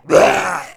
spawners_mobs_uruk_hai_death.2.ogg